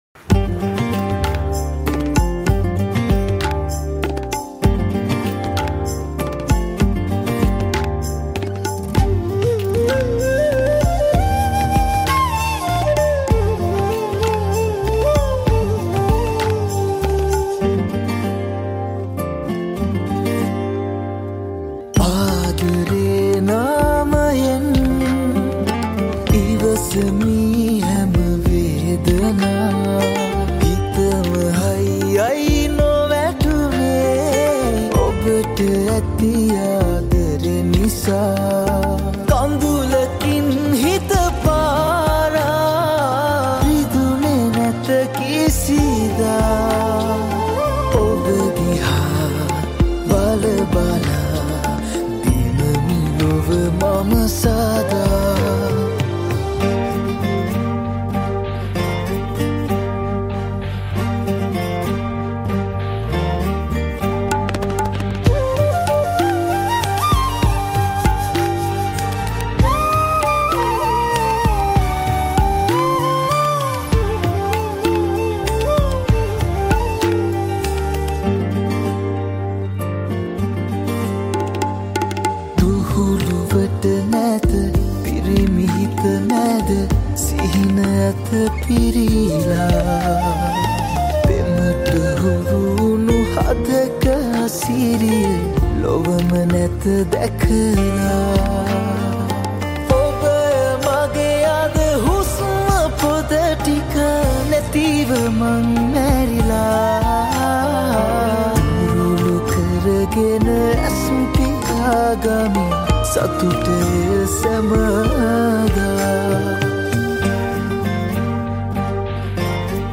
High quality Sri Lankan remix MP3 (3.5).
remix